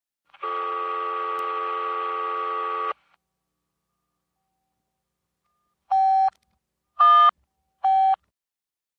Car phone dial 11 digits, send, in-line ringing, 3 digits